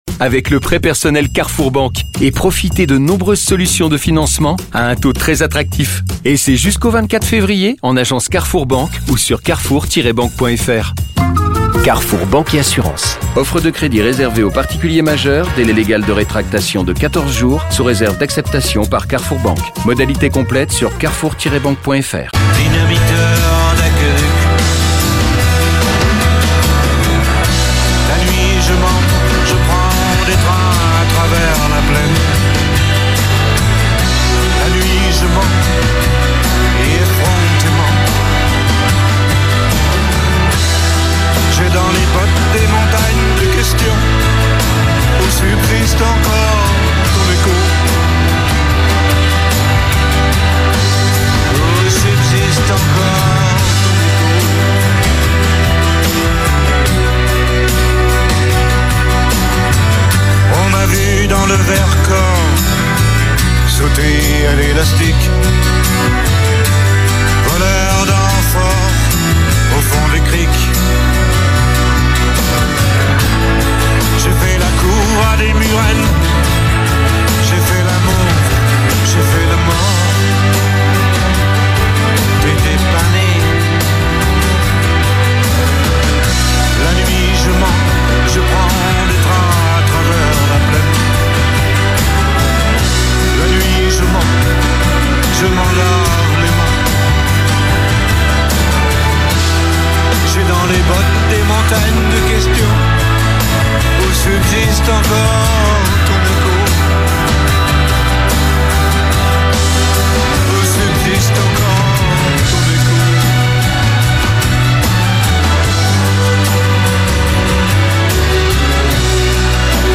Journal du lundi 17 février (midi)